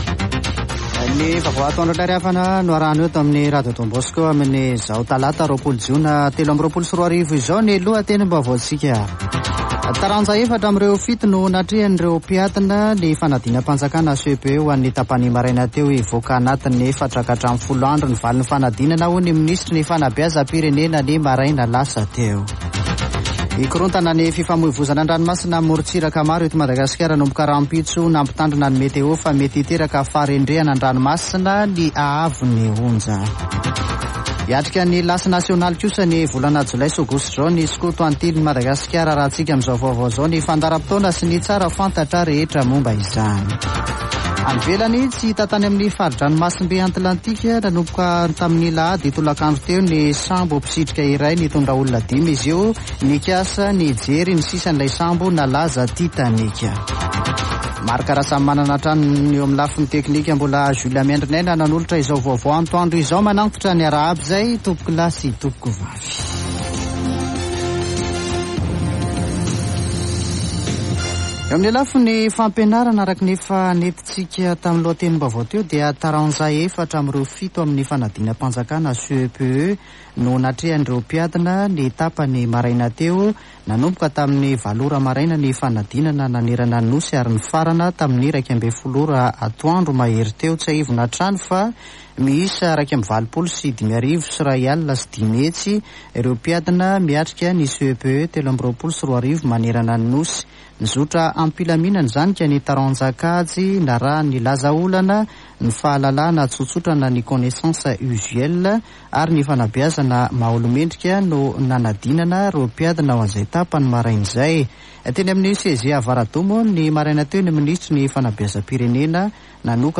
[Vaovao antoandro] Talata 20 jona 2023